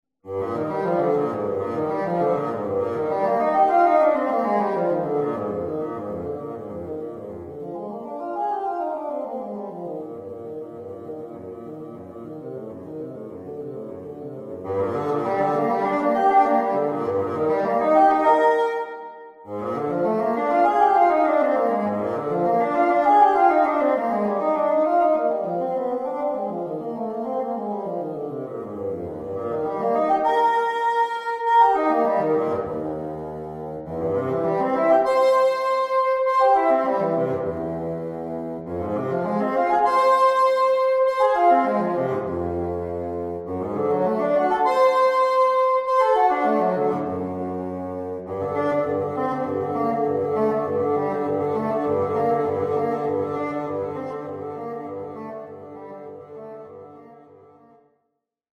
Bassoon Studies